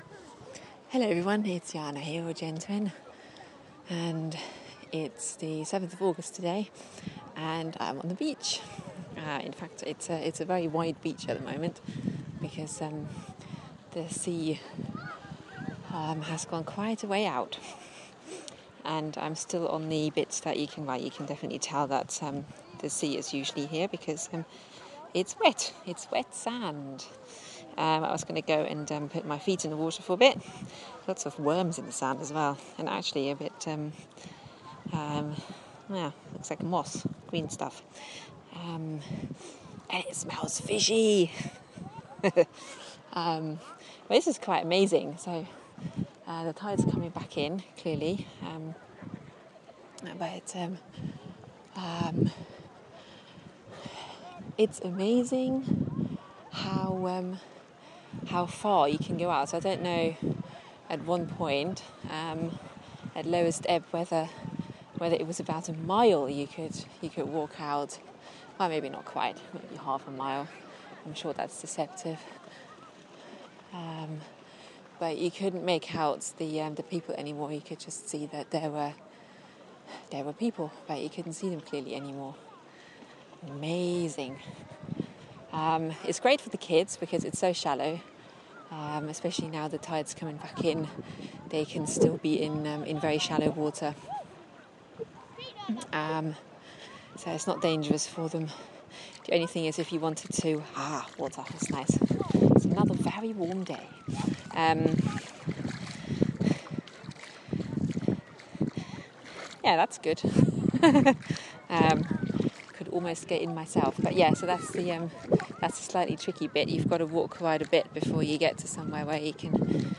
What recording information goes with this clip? A ramble while enjoying the beach